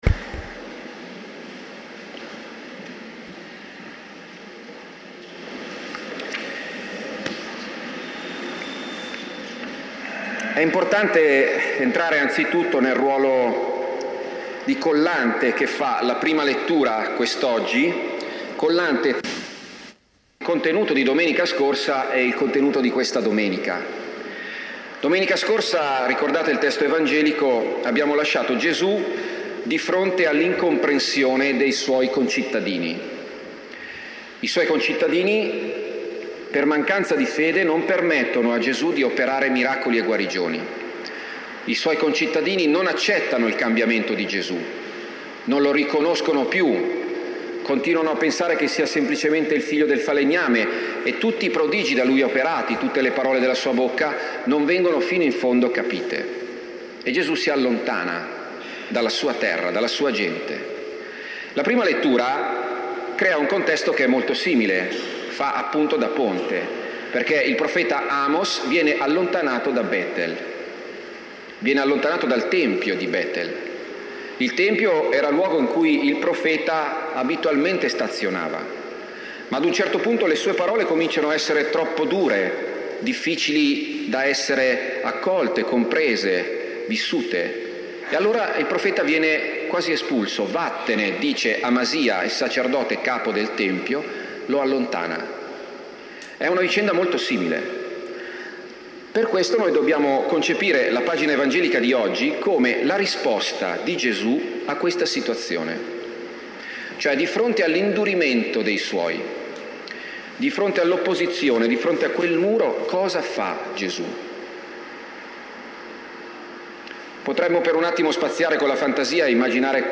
Omelia domenica 12 luglio